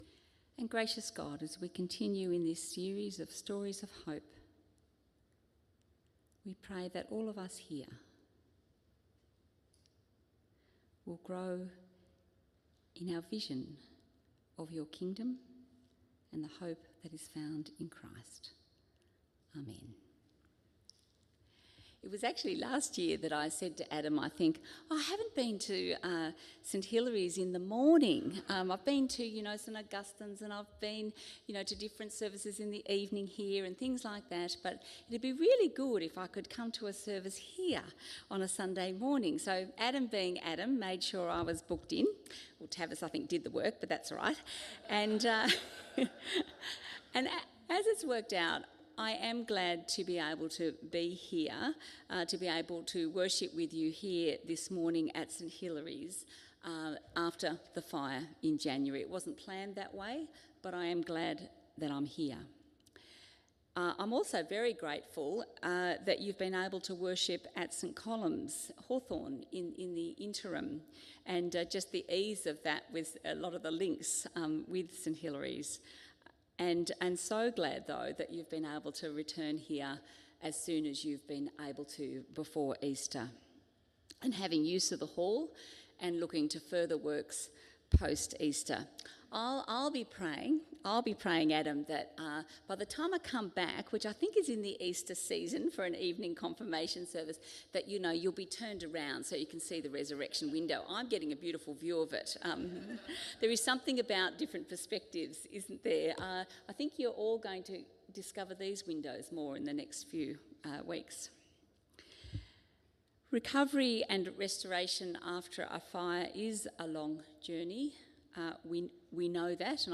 Sermon preached by Bishop Genieve Blackwell on Sunday 23 March 2025 on Luke 17:1-19